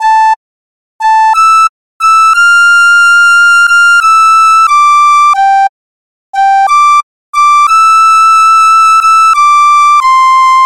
波浪形的合成器循环
描述：只是一个合成长笛
Tag: 90 bpm LoFi Loops Synth Loops 1.80 MB wav Key : Unknown Logic Pro